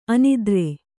♪ anidre